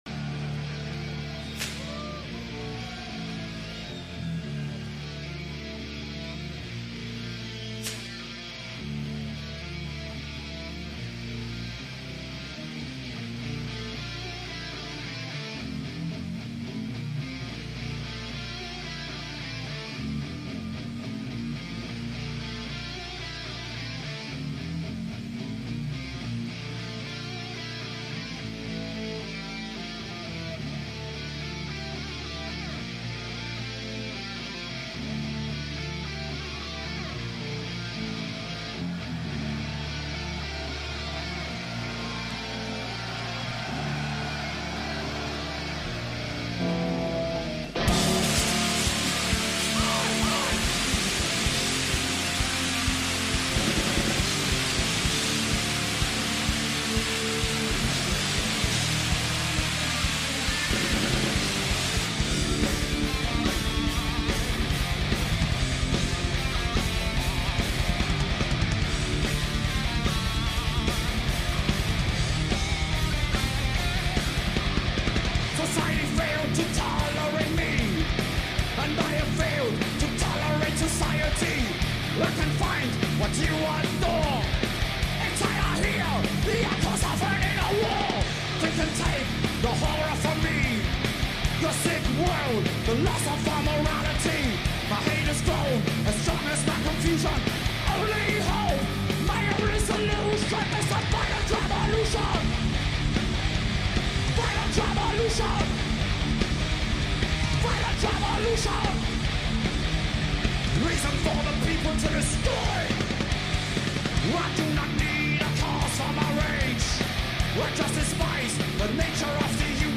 треш-метал